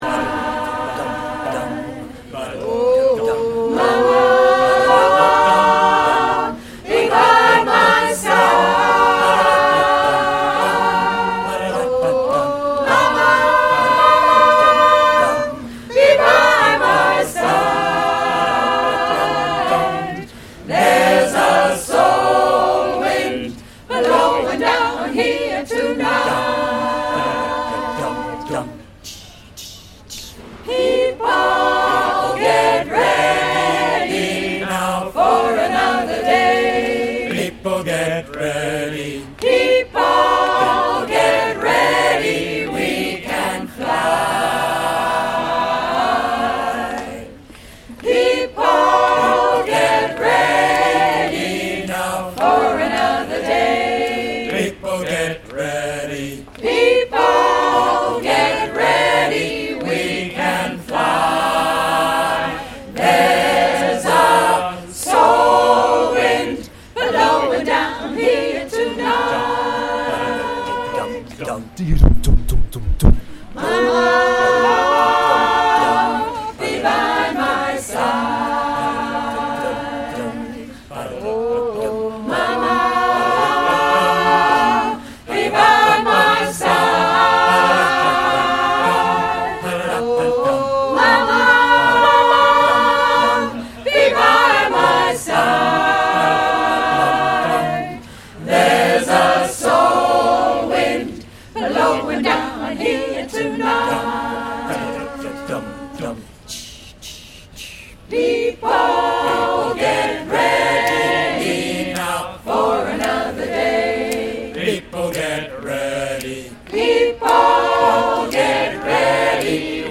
The recordings are a very rough and I’d forgotten to turn off autogain (doing two things at once: very silly) while recording, so I’ll hope you forgive the brassy sound.